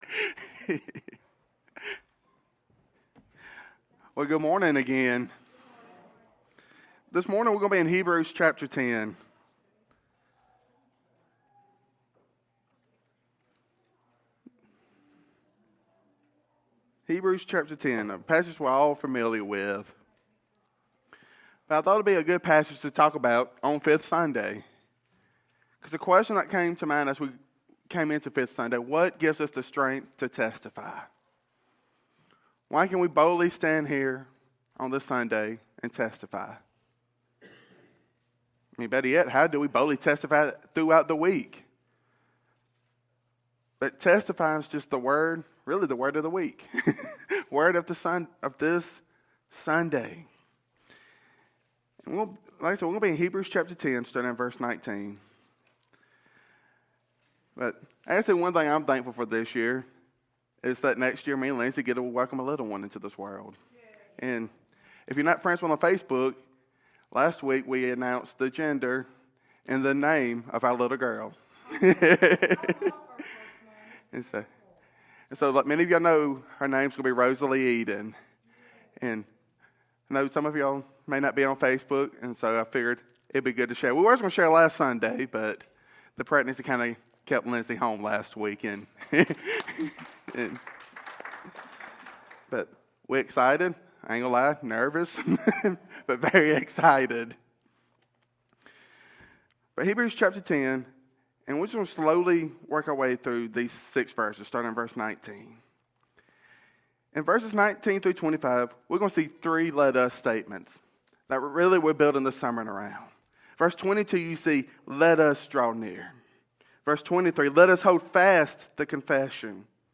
Our Confidence in Christ | Hurricane Baptist Church